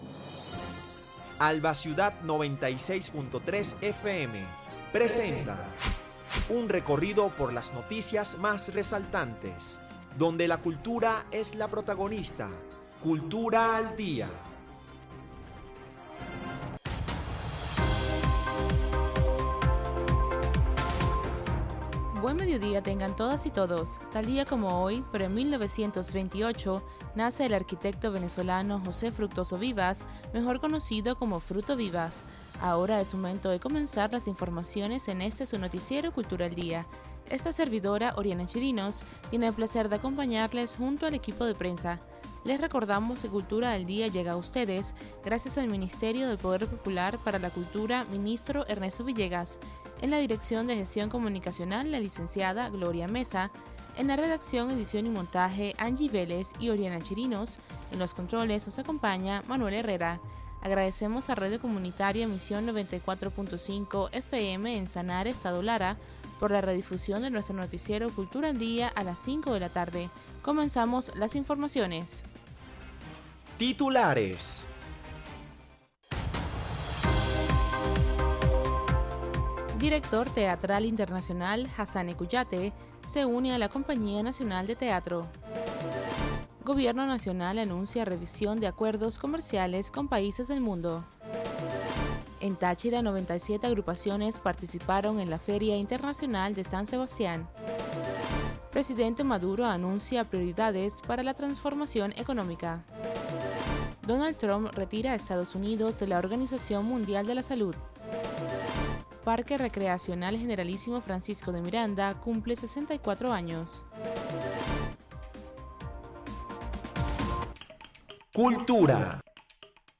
Noticiero de Alba Ciudad. Recorrido por las noticias más resaltantes del acontecer nacional e internacional, dando prioridad al ámbito cultural.